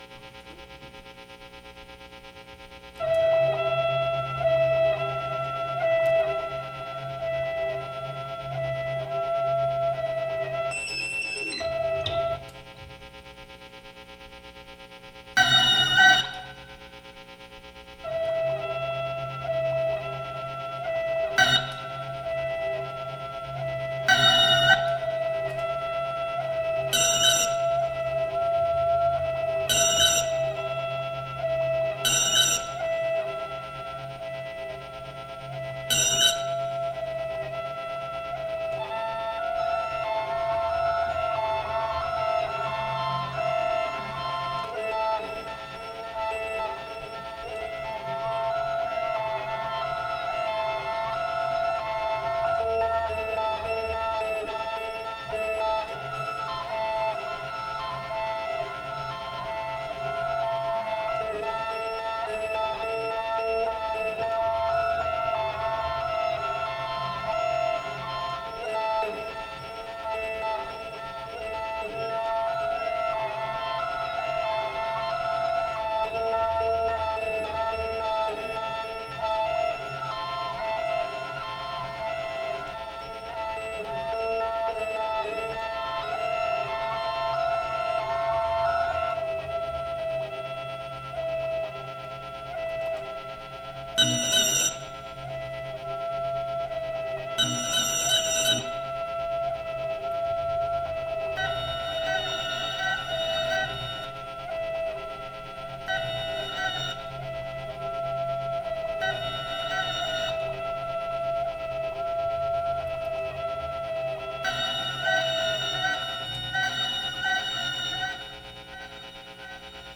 Recorded live at One Arm Red in DUMBO, Brooklyn, NY
alto clarinet, drum sticks, metal ball, vocal, fx
sampler, bass, percussion, fx
Stereo (722 / Tascam / Pro Tools)